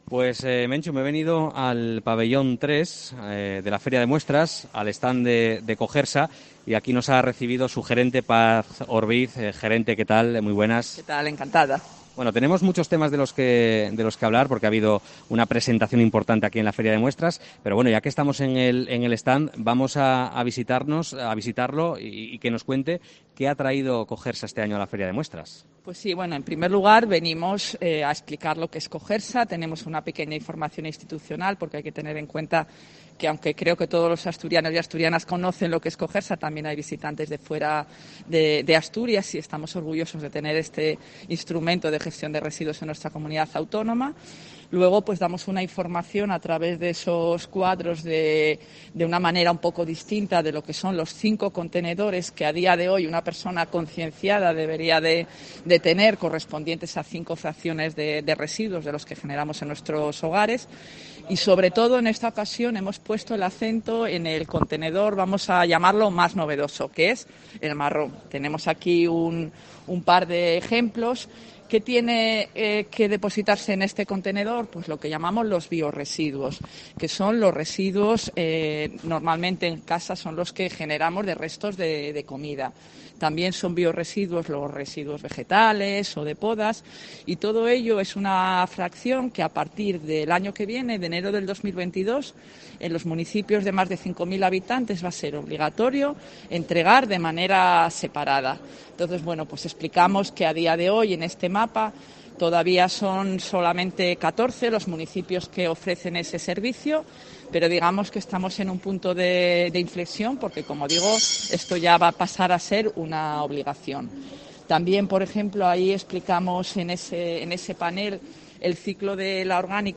Entrevista
en la Feria de Muestras